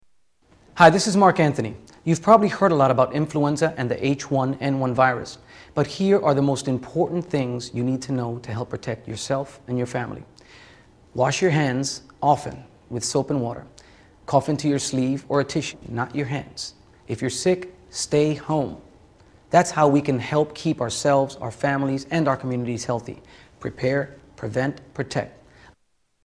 Marc Anthony PSA
Tags: Media Flu PSA's Flu Public Service Announcements H1N1